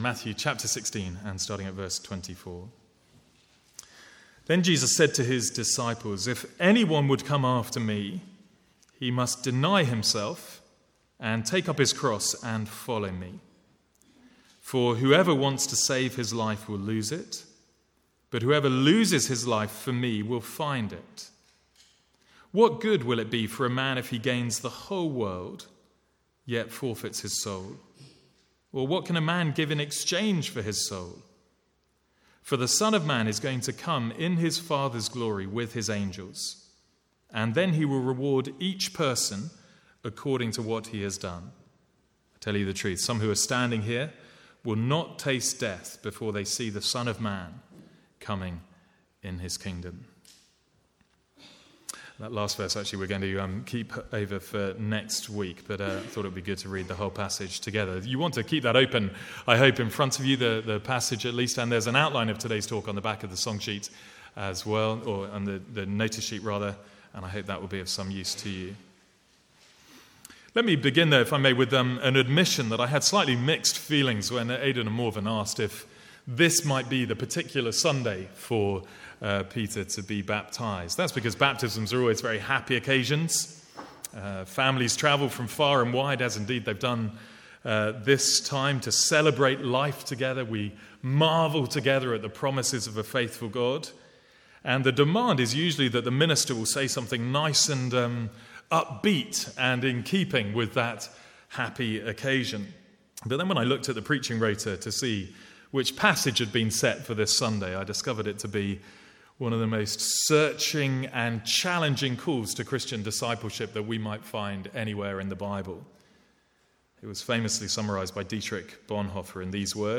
From the Sunday morning series in Matthew.